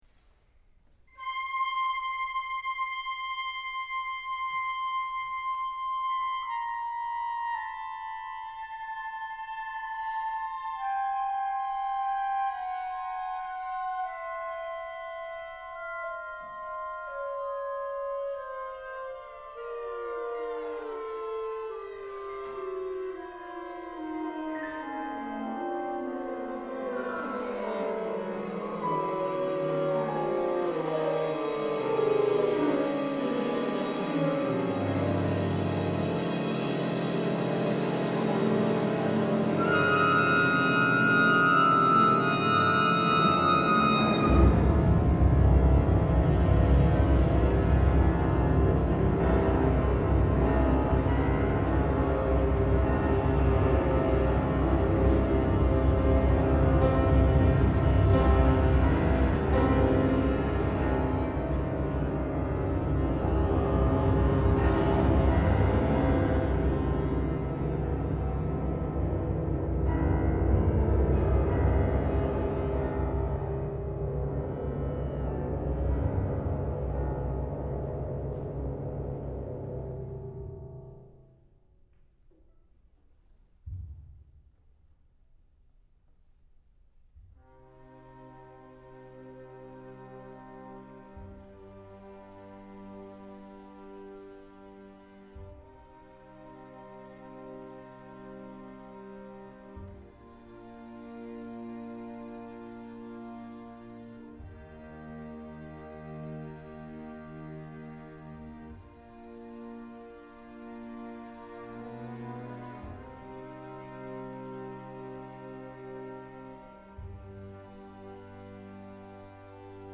Scored for flute, oboe, clarinet, bassoon, prepared piano, harpsichord, portative organ, 4 violins, 2 violas, 2 cellos, bass and percussion.
for Chamber Orchestra Scored for flute, oboe, clarinet, bassoon, prepared piano, harpsichord, portative organ, 4 violins, 2 violas, 2 cellos, bass and percussion.
It is a polystylistic romp through the ages, built on three pieces of material: a descending glissando figure, the “La Folia” chord progression, and a waltz.
As the air decompresses, various higher partials in the pipes are activated, creating about the coolest instrumental effect I know.